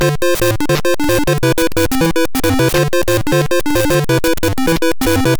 In addition, the Sharp X1 version is slightly faster.